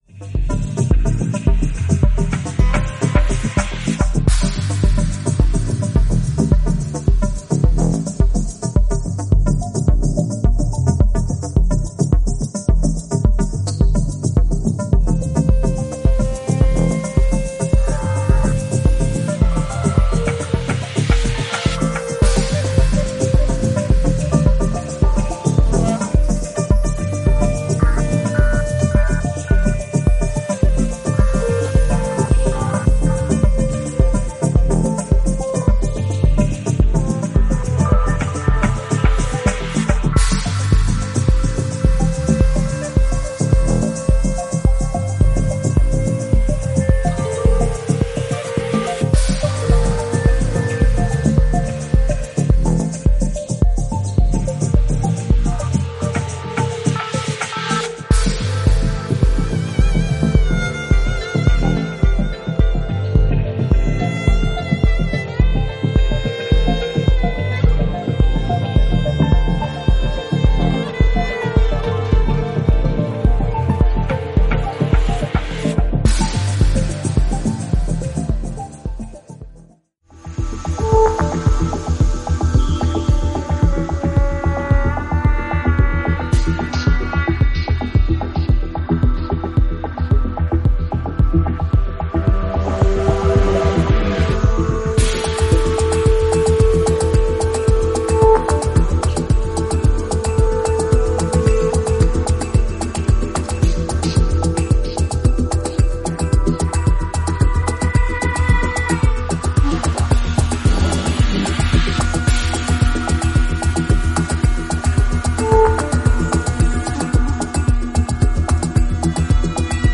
ディープハウス〜テクノをベースにしながらもジャンルのカテゴライズは絶対不可能な独創的なサウンドを作り出すことに成功